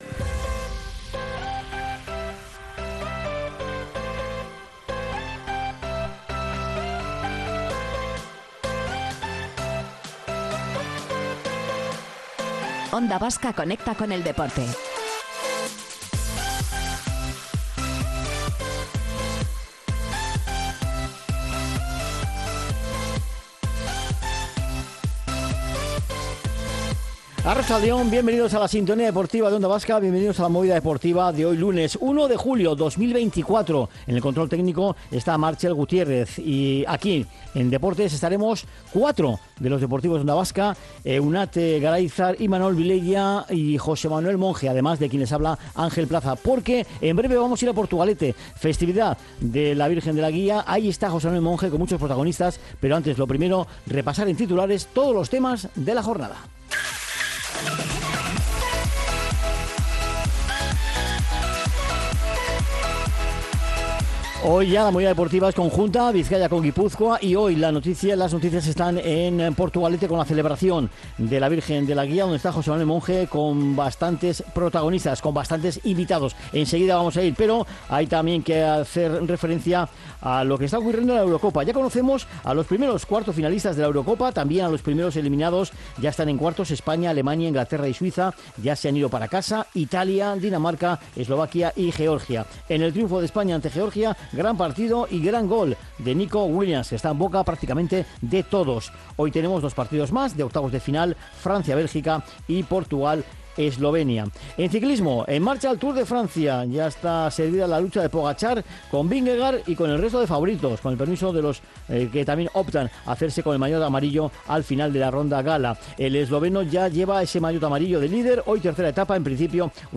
Hemos comenzado el programa de hoy desde Portugalete donde se celebraba 'La Virgen de la Guía'.